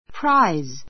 práiz